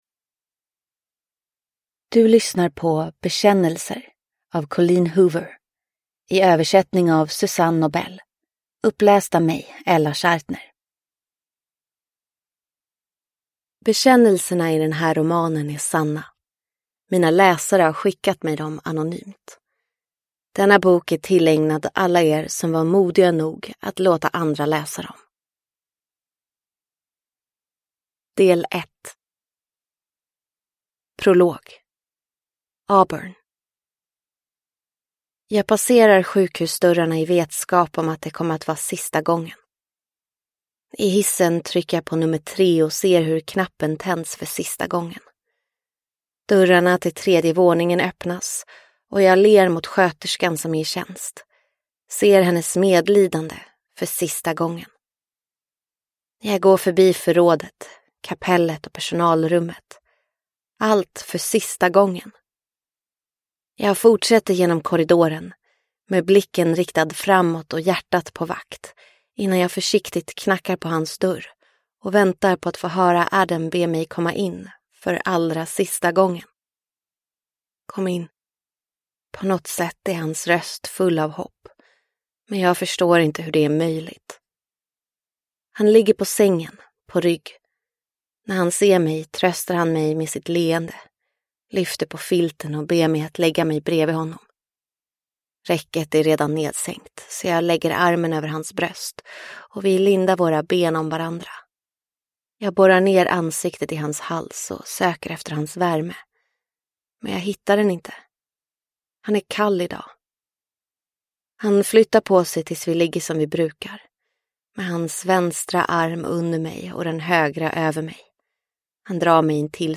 Bekännelser (ljudbok) av Colleen Hoover